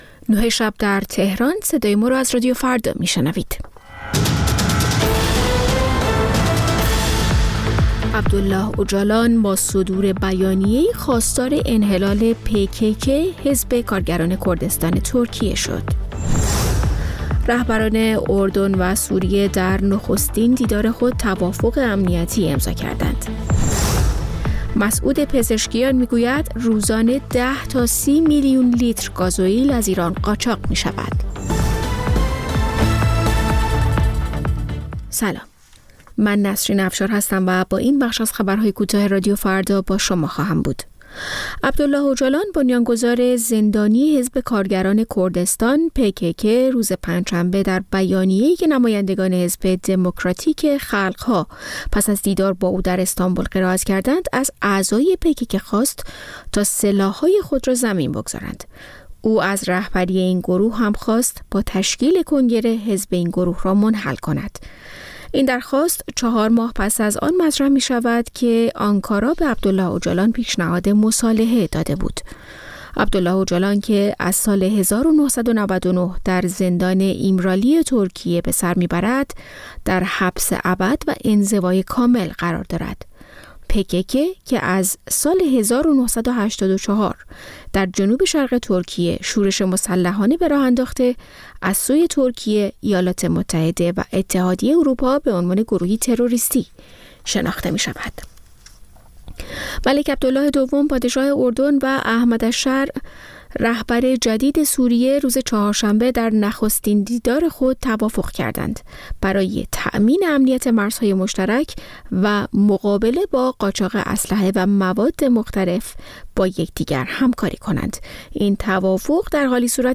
سرخط خبرها ۲۱:۰۰